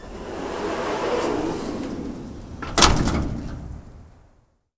GARAGE 2.WAV